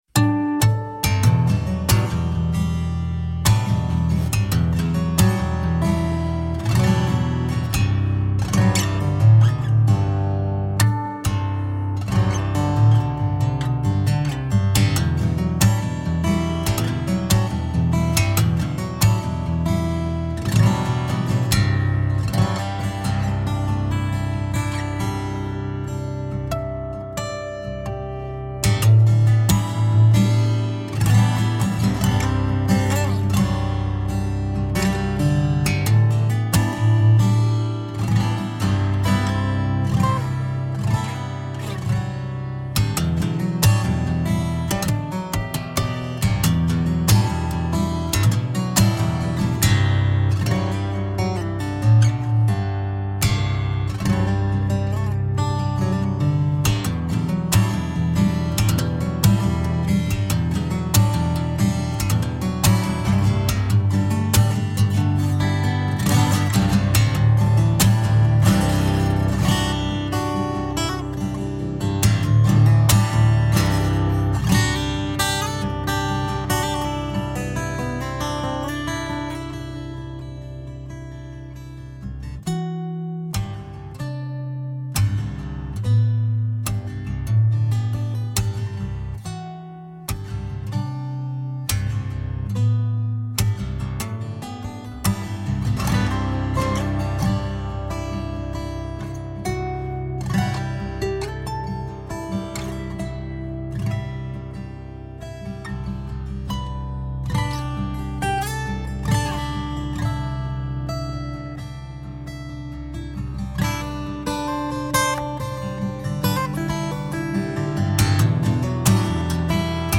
Acoustic percusive guitar.
Tagged as: Alt Rock, Guitar, Instrumental, Folk